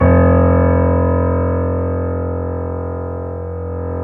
Index of /90_sSampleCDs/Roland L-CD701/KEY_YC7 Piano pp/KEY_pp YC7 Mono